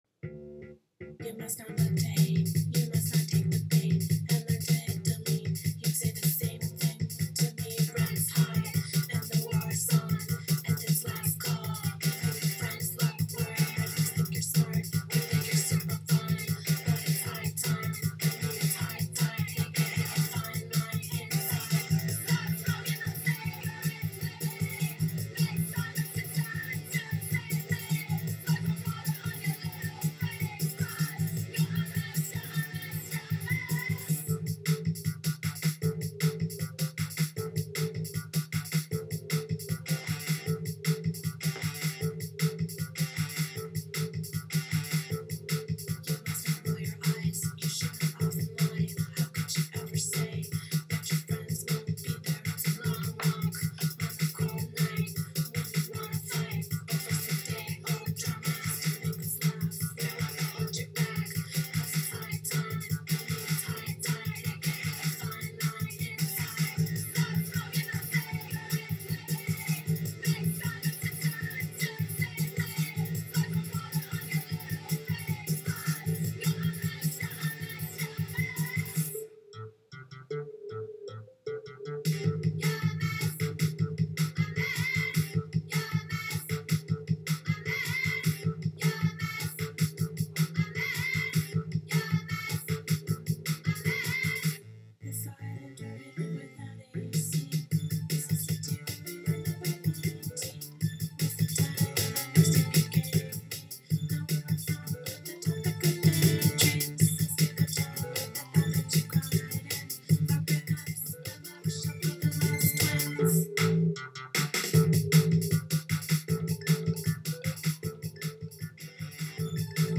..Ένα Queer Dance Party από το Queericulum Vitae (QV)
Ακούσαμε αρκετή electro-punk, reggae, και ska.